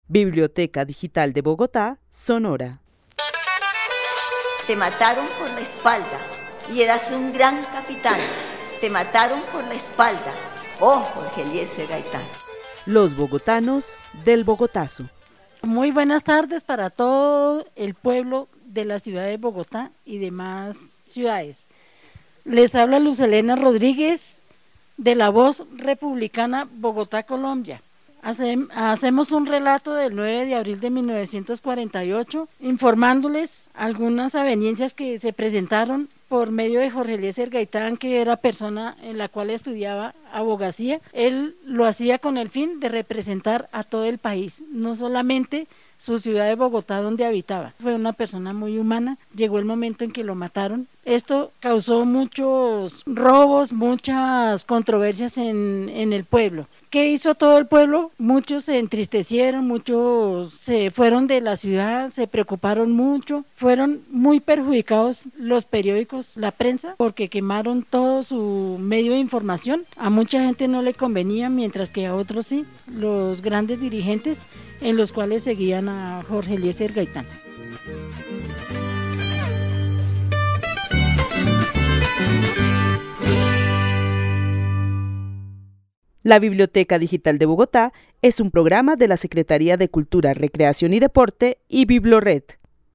Narración oral de los hechos sucedidos el 9 de abril de 1948. La narradora es un mujer de 58 años, aproximadamente, quien cuenta lo que representaba Jorge Eliecer Gaitán y las consecuencias de su asesinato.